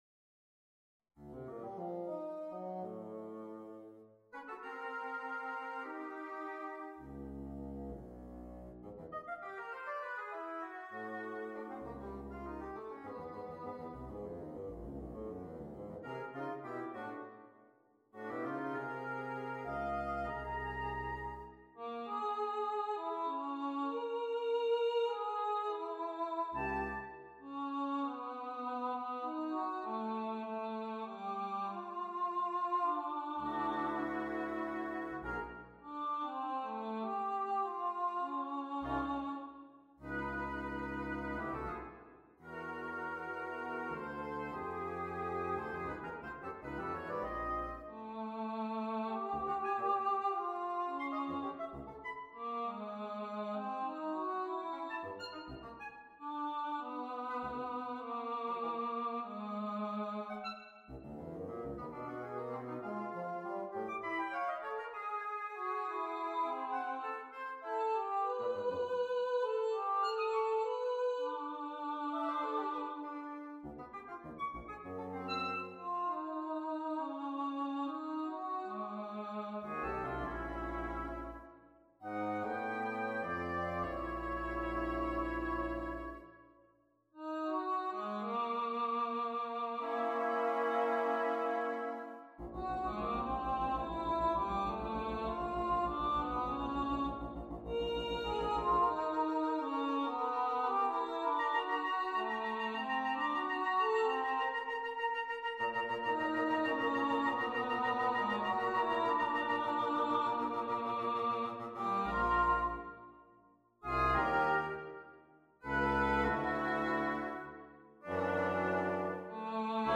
on a purpose-selected tone row
C-Ab-F-Eb-G-Bb-Db-B-A-Gb-E-D